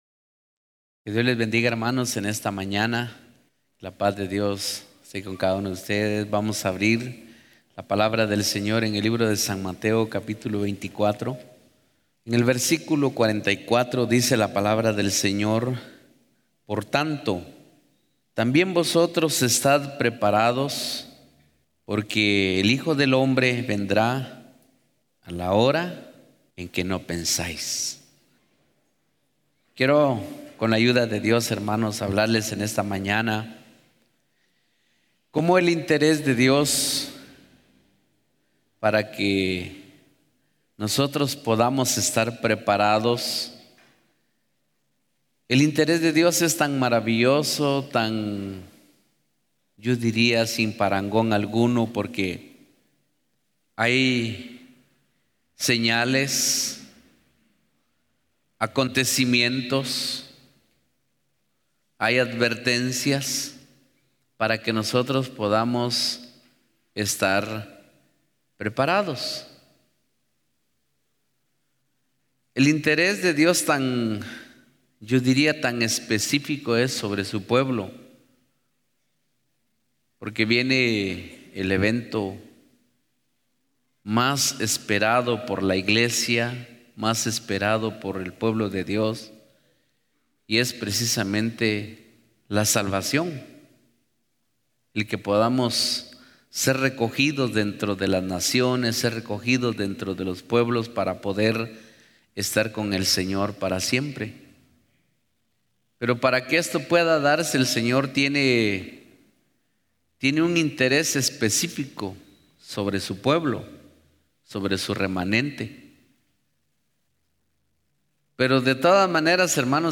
Audio de la Prédica